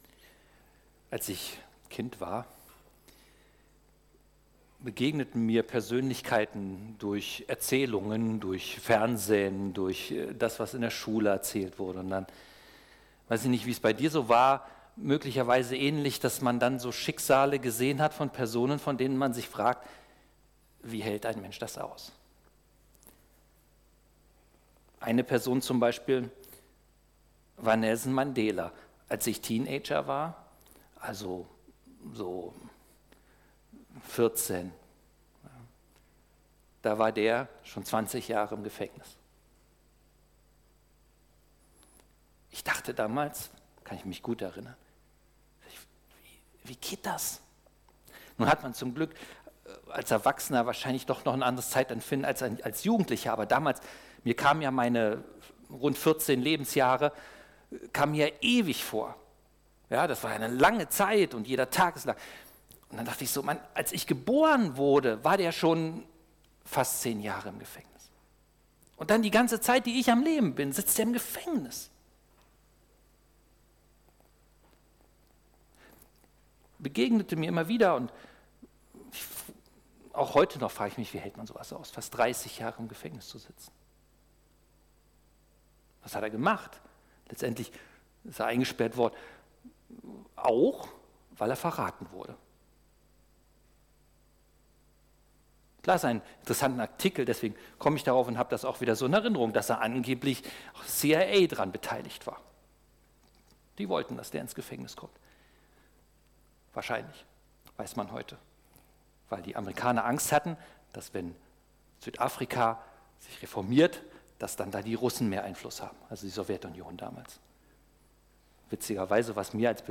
2025 in Gottesdienst Keine Kommentare 186 LISTEN